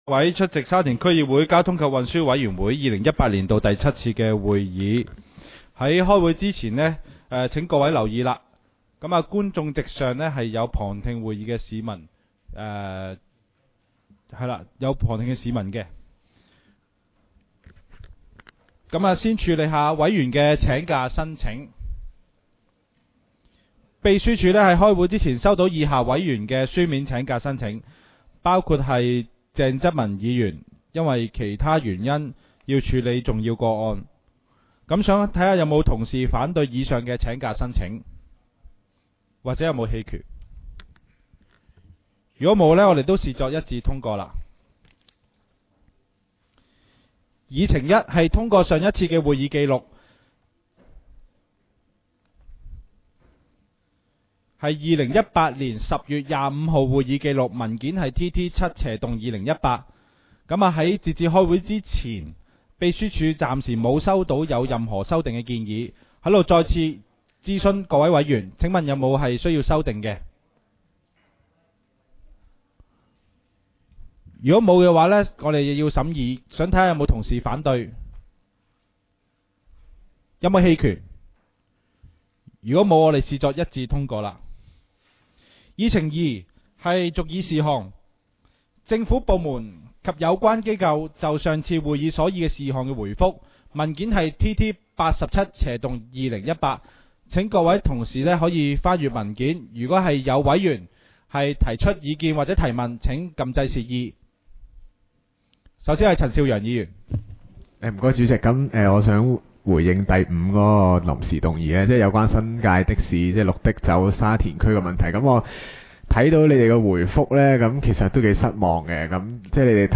沙田区议会 - 委员会会议的录音记录
委员会会议的录音记录